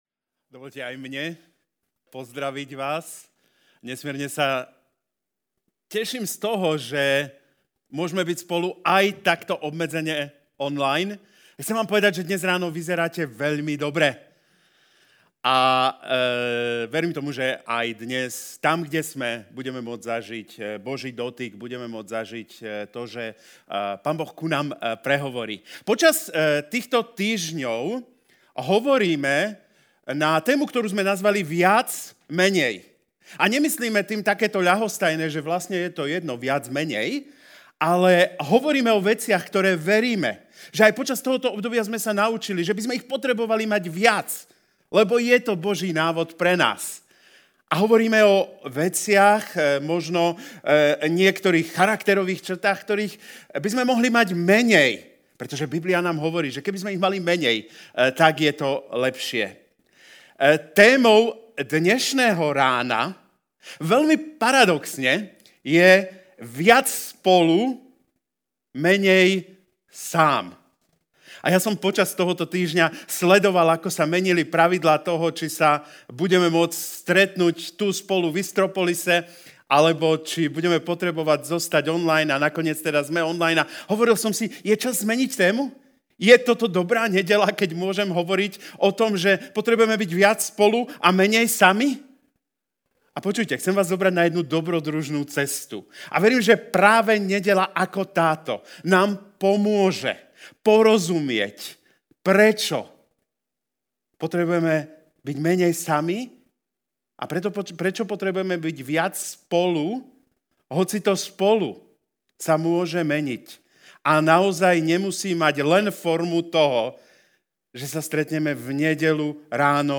Čo hovorí Biblia o vzťahoch? Aj na to sa pozrieme v dnešnej kázni.